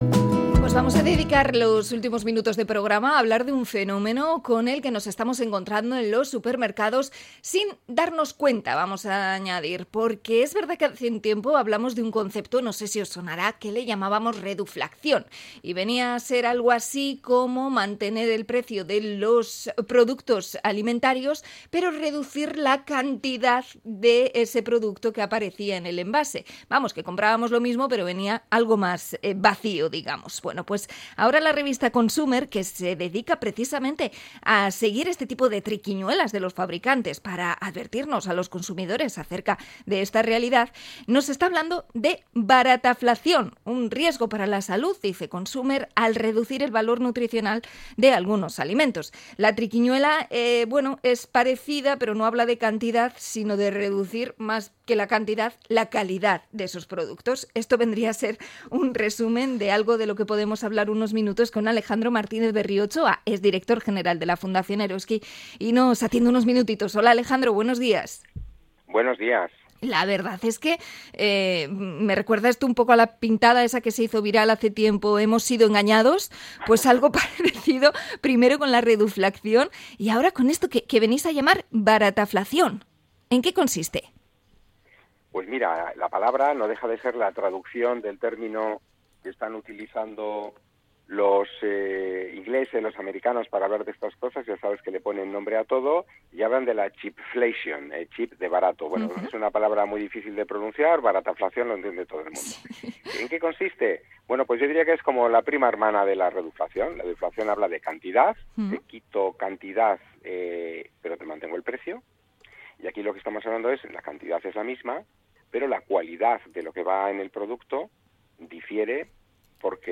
Entrevista a Consumer por la técnica de la barataflación